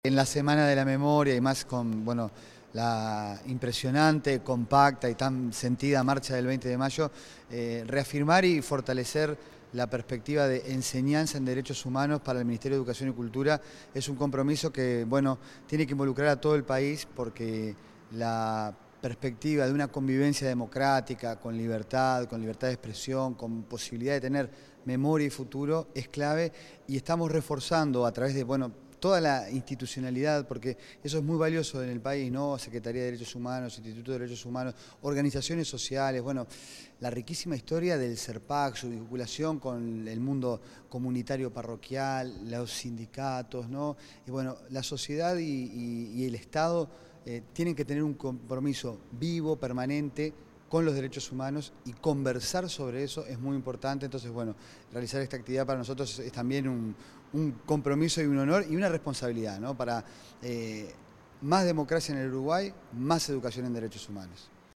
Declaraciones del director nacional de Educación, Gabriel Quirici
El director nacional de Educación, Gabriel Quirici, dialogó con la prensa luego de participar en el conversatorio Educación en Derechos Humanos.